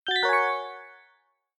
disinfect_2.mp3